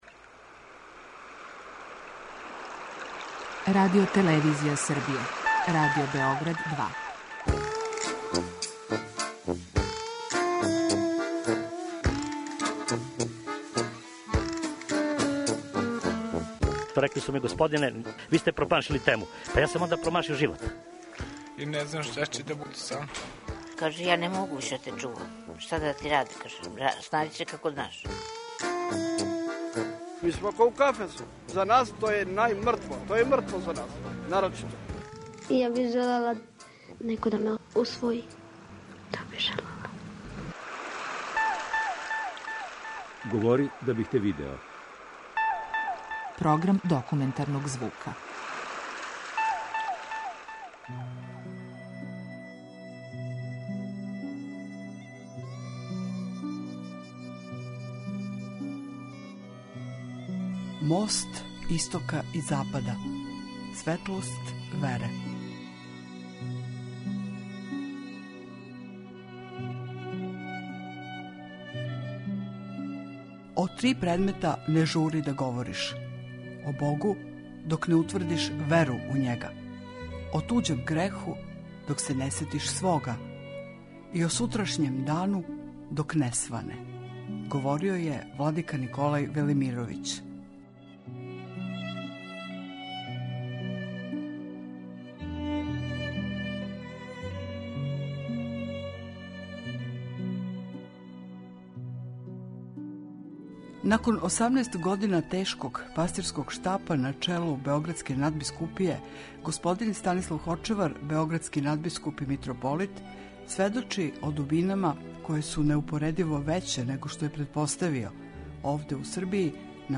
Документарни програм
Уочи Ускрса, највећег хришћанског празника, београдски надбискуп и митрополит Станислав Хочевар говори о суштини вере, о дубоким духовним пределима које није очекивао да ће пронаћи у Србији, коју сматра мостом истока и запада.